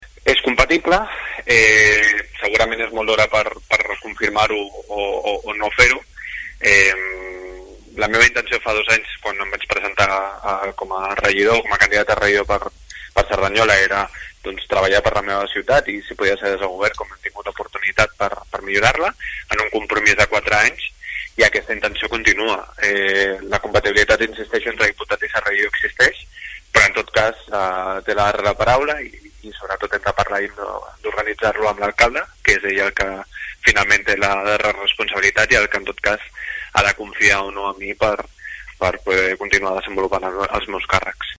Declaracions de David González: